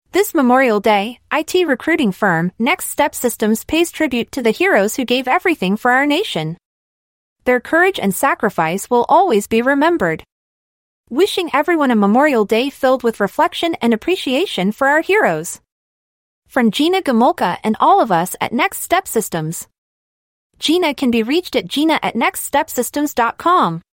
A Happy Memorial Day Message from IT Recruiting Firm, Gina’s Tech Jobs Using Artificial Intelligence (AI)
Please take a moment to listen to a Happy Memorial Day audio message from our IT recruiting firm, Gina’s Tech Jobs generated by Artificial Intelligence (AI). Gina’s Tech Jobs pays tribute to the heroes who gave everything to our nation, and we appreciate their sacrifices.